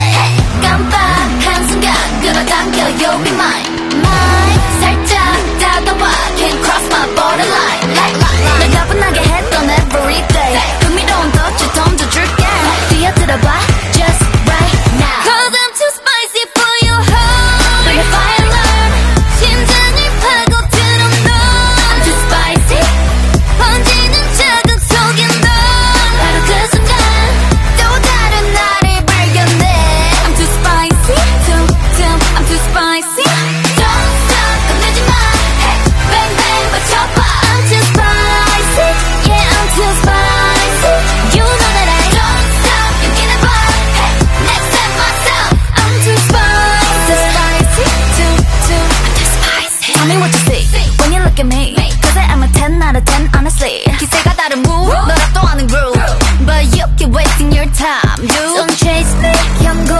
(sorry about the bad quality😅)